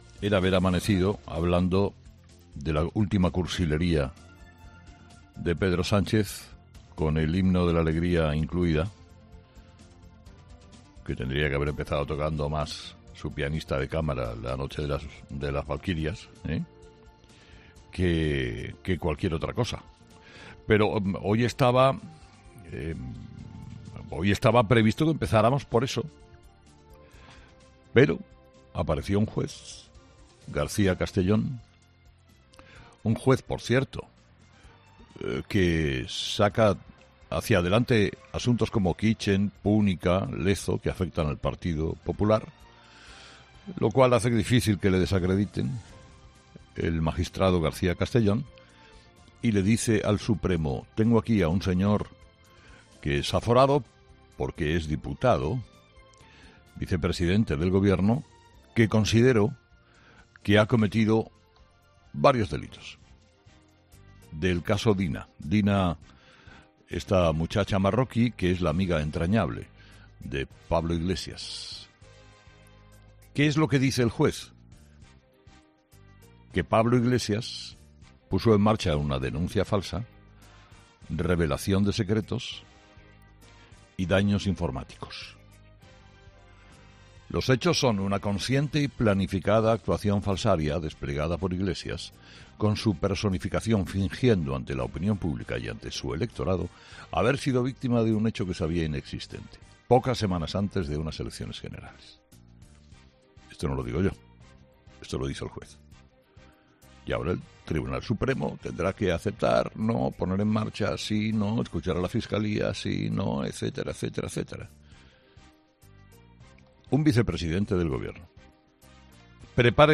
El comentario de Herrera sobre Pablo Iglesias y su posible imputación, entre lo mejor de 'Herrera en COPE'
El director y presentador de 'Herrera en COPE', Carlos Herrera, ha analizado esta semana las últimas informaciones sobre el líder de Unidas...